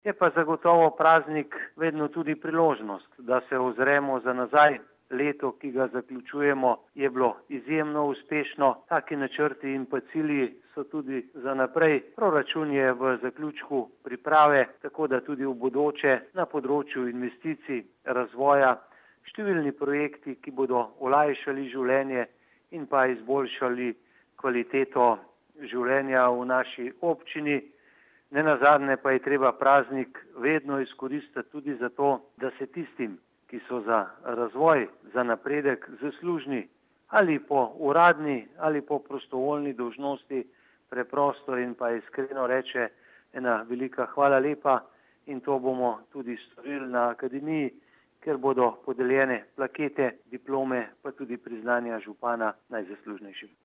76447_izjavazupanaobcinetrzicmag.borutasajovicaobobcinskemprazniku.mp3